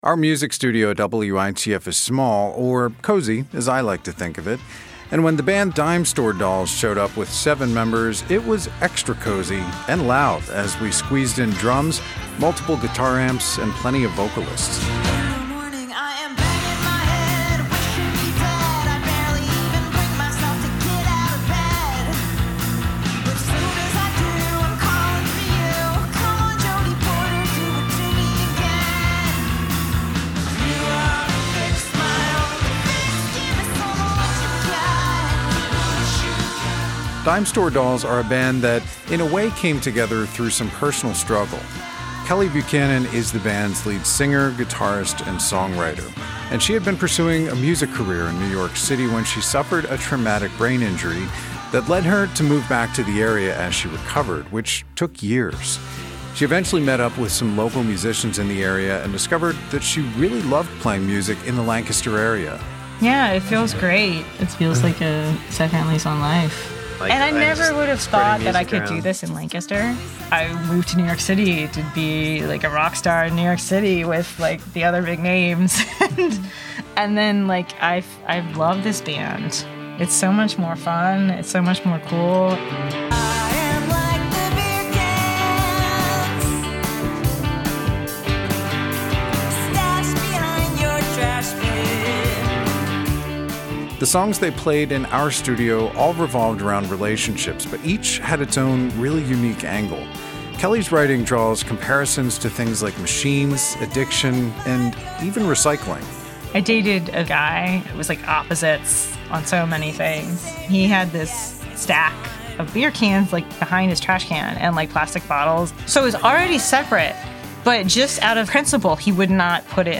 drums
guitar